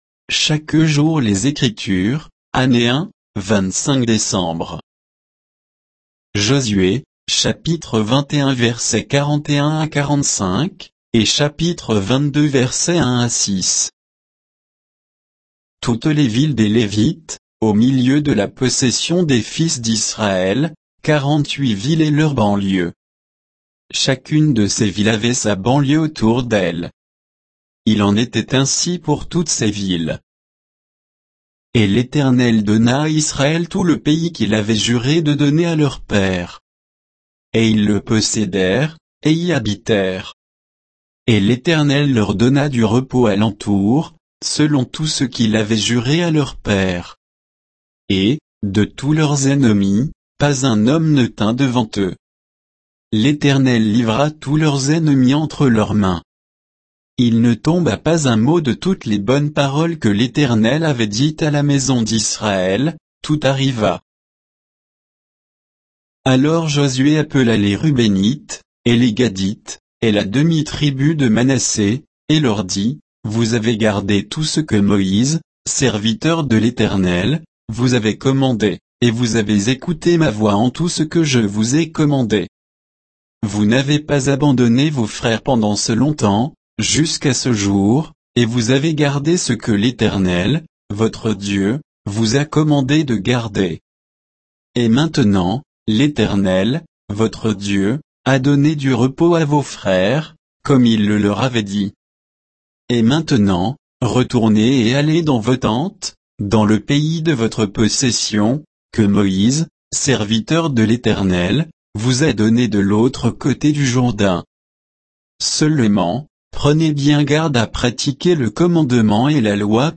Méditation quoditienne de Chaque jour les Écritures sur Josué 21